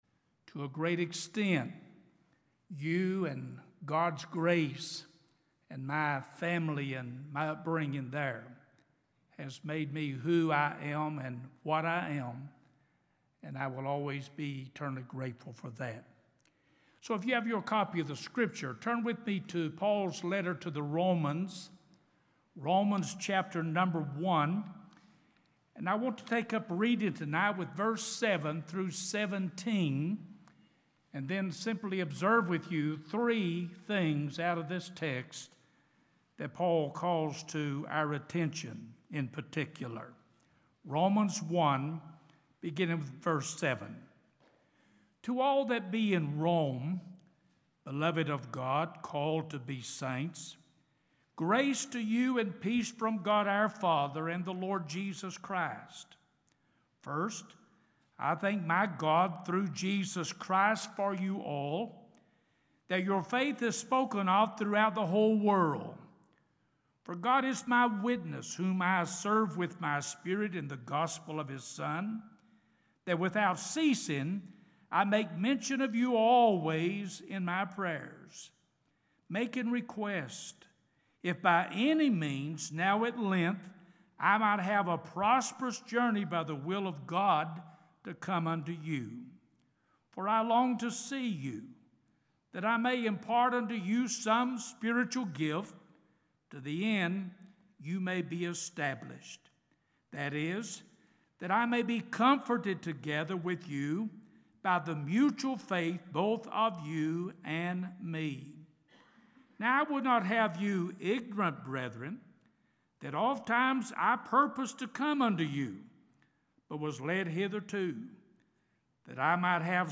December 2011 Sermon Library